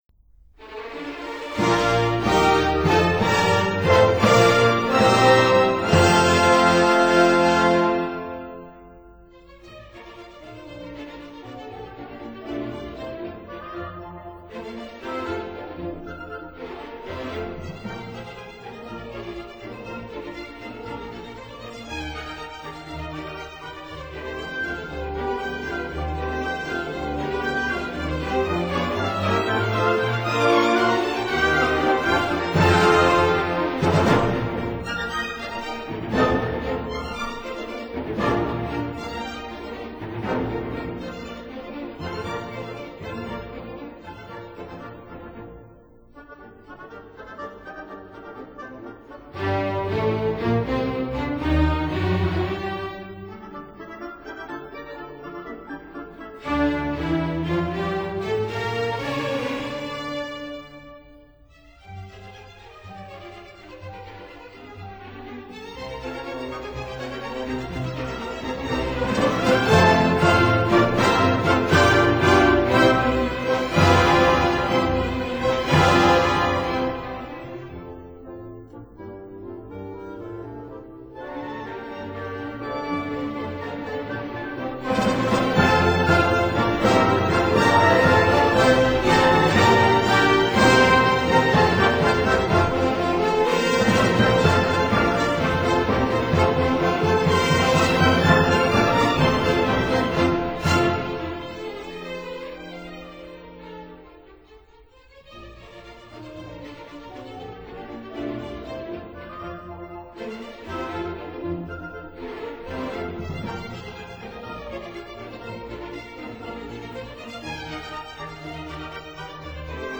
horn *
on period instruments